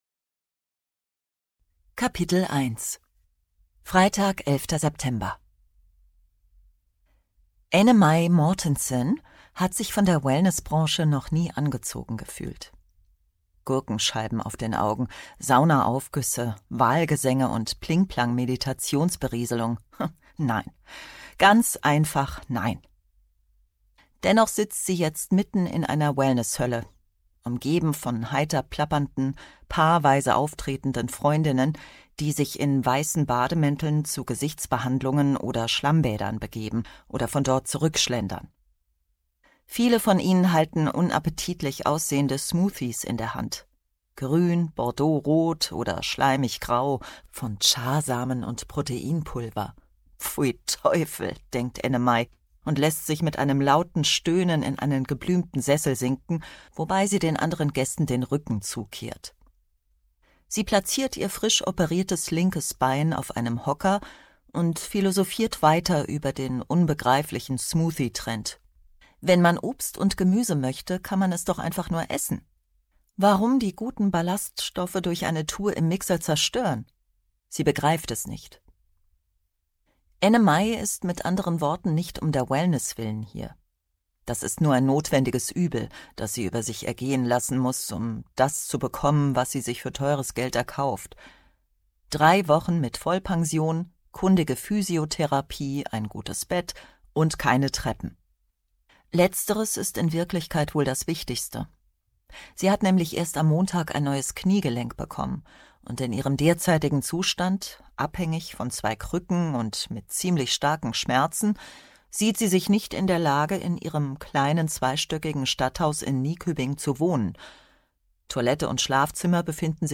Hygge-Krimi